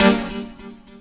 SYNTH GENERAL-3 0003.wav